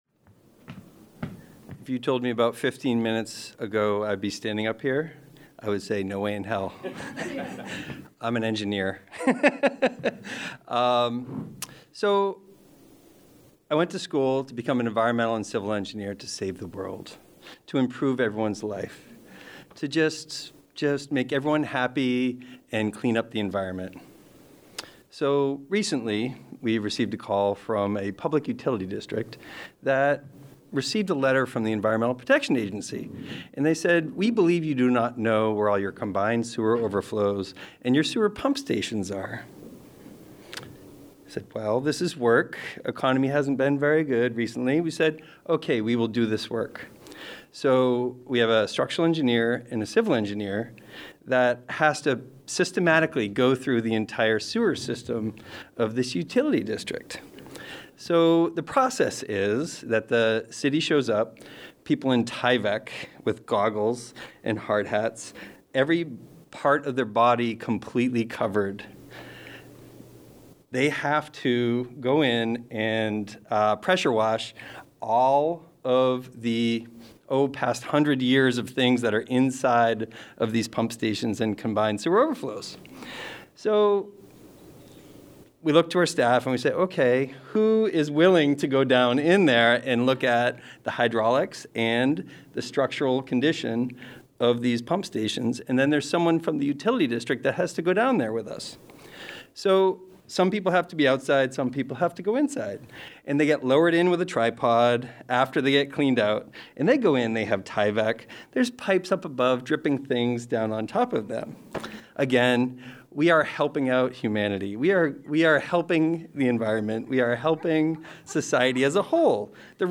Slam Final
After our main-stage storytellers at “Take This Job And Shove It”, five audience members took the stage with their own five-minute tales of crappy work.